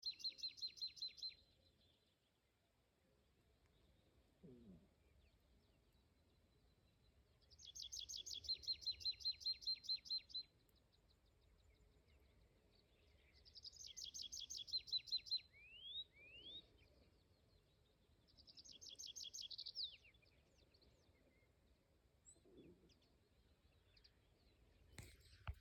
Tree Pipit, Anthus trivialis
Administratīvā teritorijaValkas novads
StatusSinging male in breeding season